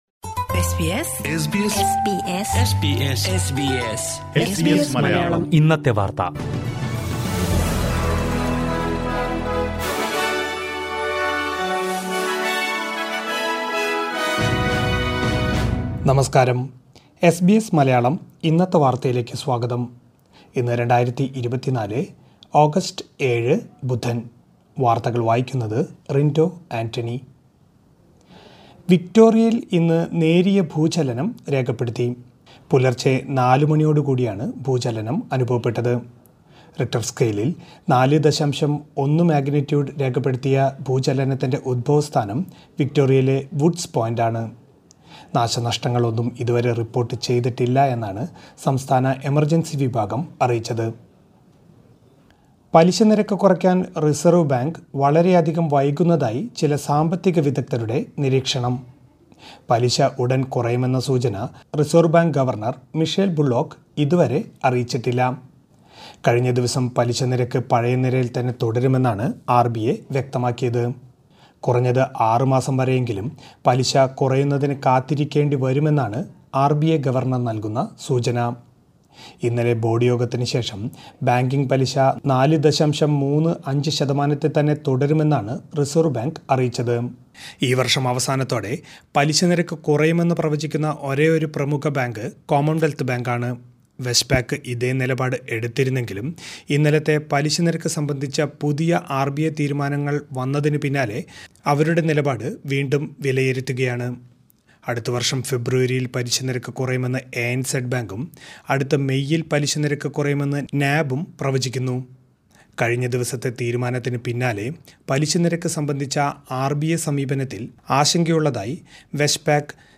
2024 ഓഗസ്റ്റ് ഏഴിലെ ഓസ്‌ട്രേലിയയിലെ ഏറ്റവും പ്രധാന വാര്‍ത്തകള്‍ കേള്‍ക്കാം...